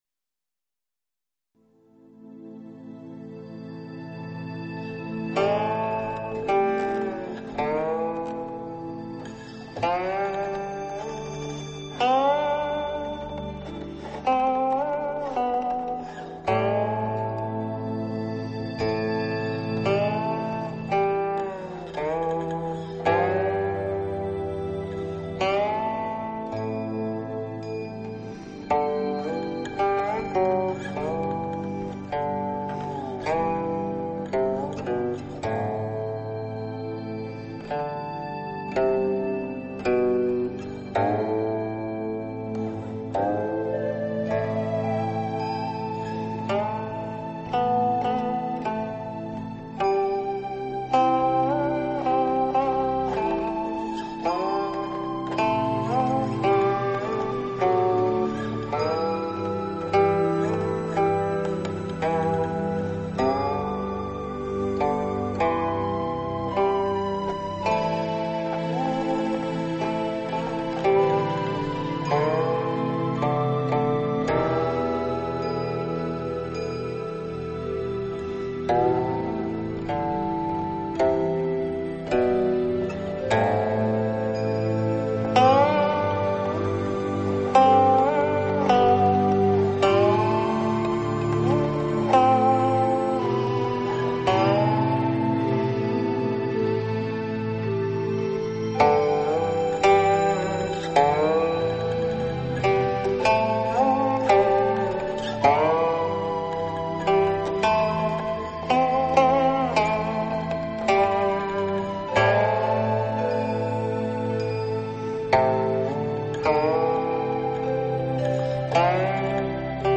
二胡、古筝、箫、琵琶、古琴等乐器，勾勒出六种菊花的色、香、姿、韵。 雅致的乐曲、灵动的乐器，精准掌握菊花的内涵。